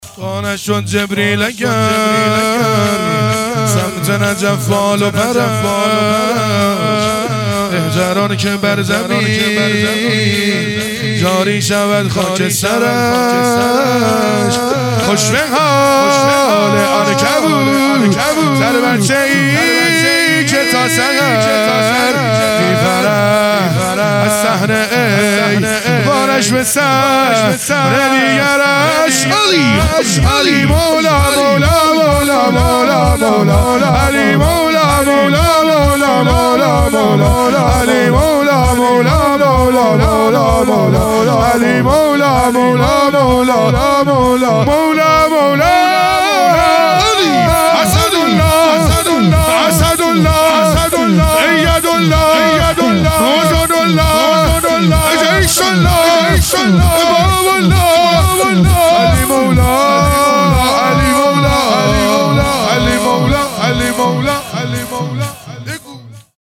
دهه دوم فاطمیه | شب سوم | شور | وا نشد جبریل اگر سمت نجف بال و پرش
دهه دوم فاطمیه 1443